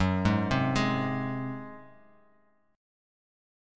Gbsus2b5 chord